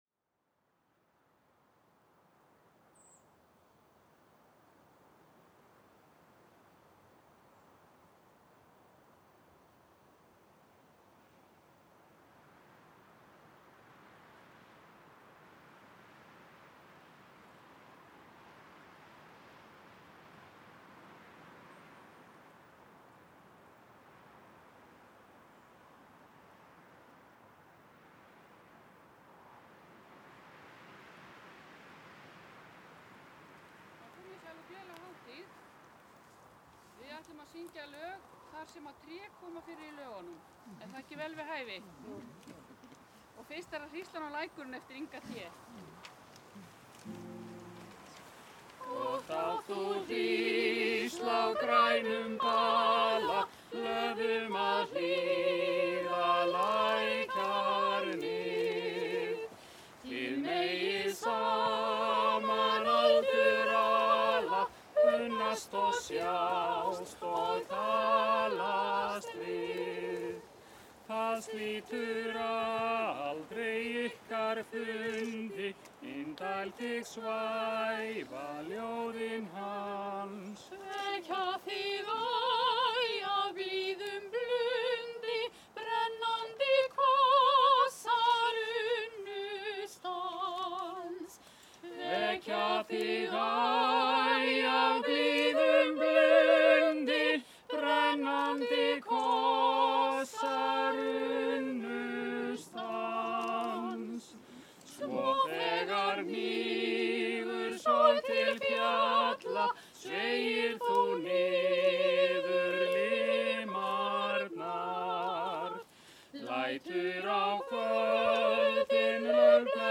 The 14th of September 2014 over 100 people met in a ceremony in Arnarholt, west of Iceland, to introduce the tree of the year.
The problem was that all the speeches were trough megaphone so the sound was awful. But the songs were ok and one speech about history of the tree too. The recording ends with another recording I made after the ceremony under the tree with binaural array and contact mics on the tree bole.
Í enda upptökunar má heyra vindinn gæla við trjátoppana og hljóðin innan úr bol trésins tekin upp með „kontakt“ hljóðnemum.